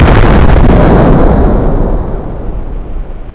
glock22-fire.wav